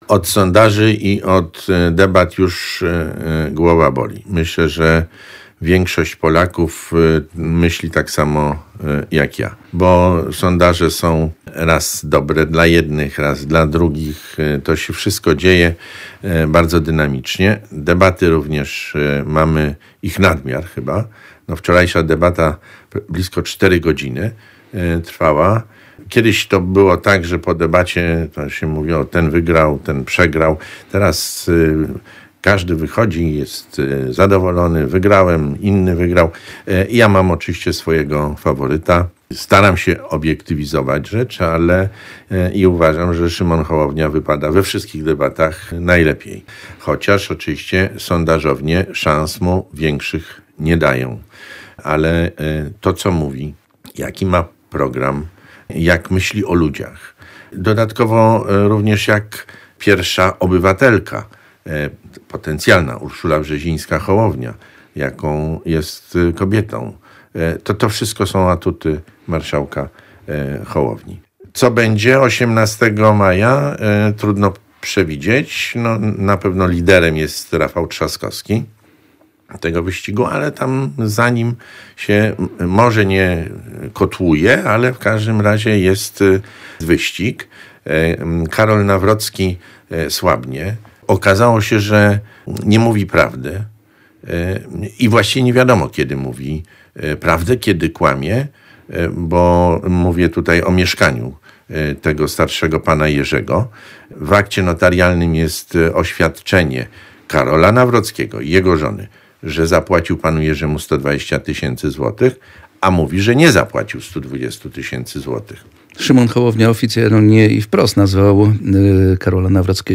Rozmowa z senatorem Jackiem Trelą